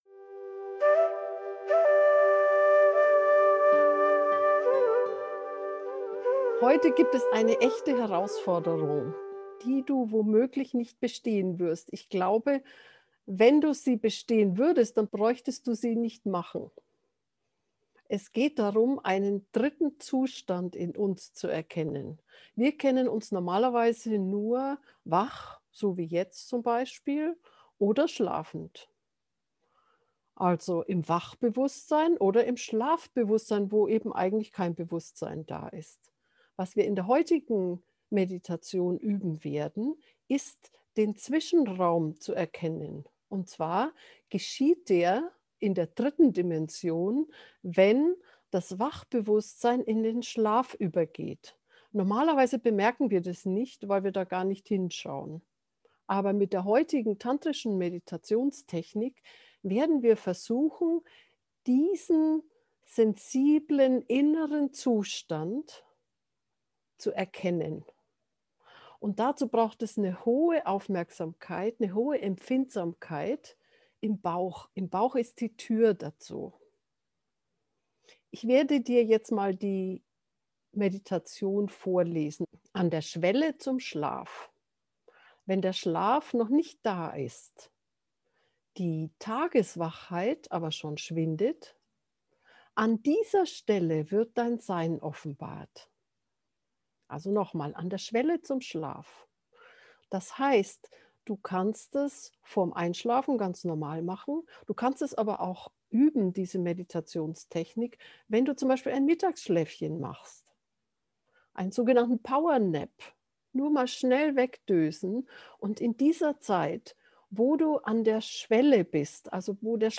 powernapping-einschlafmeditation-gefuehrte-meditation